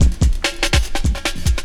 16 LOOP10 -R.wav